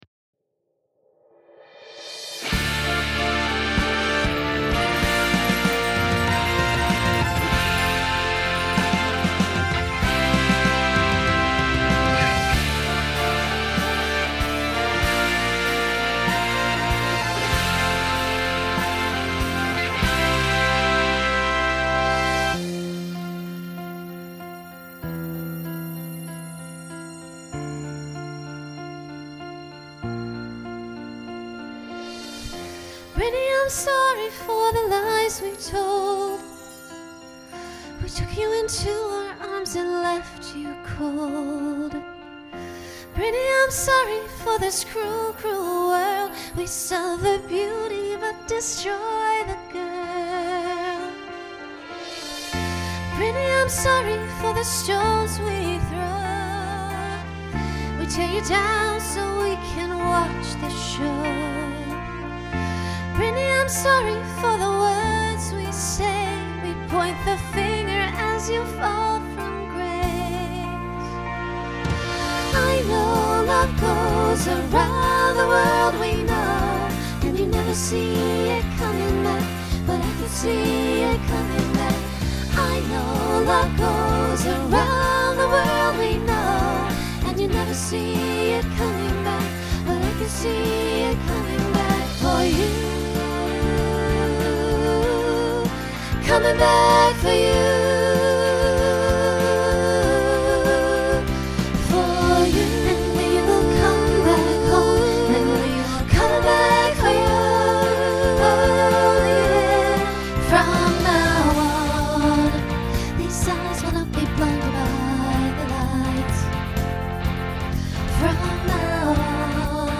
Genre Broadway/Film , Pop/Dance
Voicing SSA